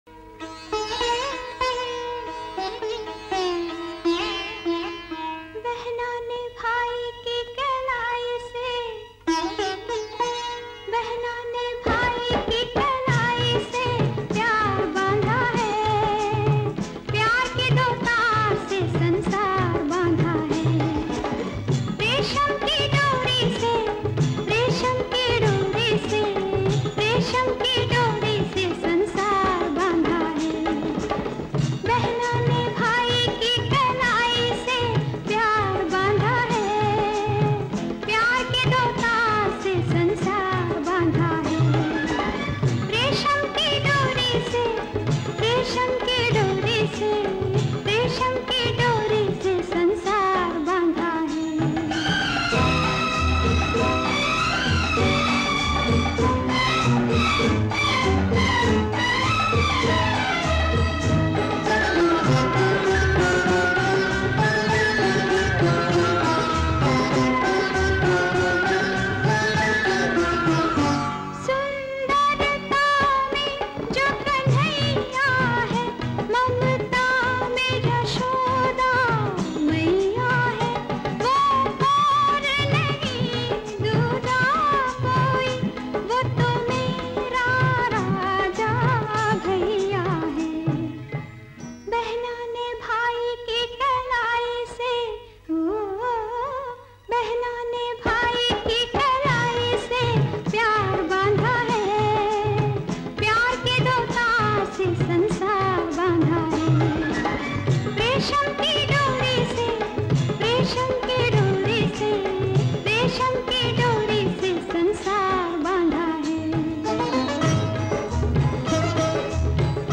This soul-stirring song